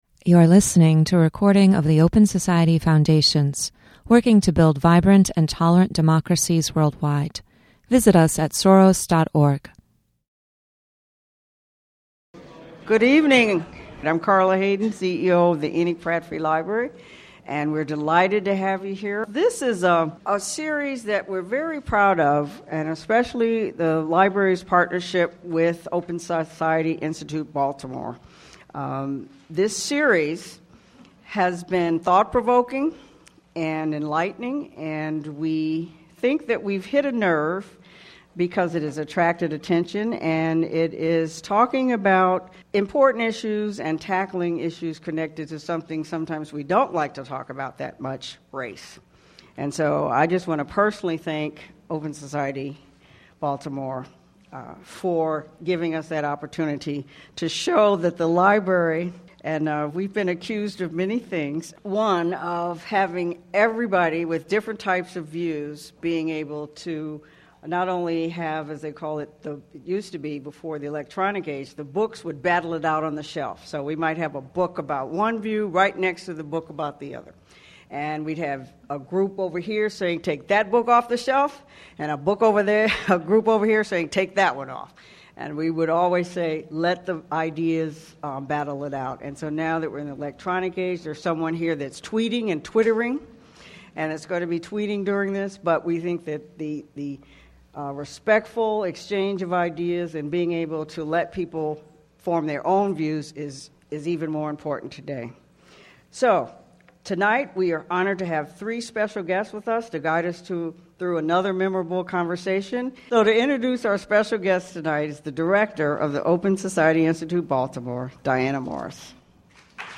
Panelists discuss the Greensboro Truth and Reconciliation Commission.